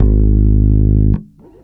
10-G#1.wav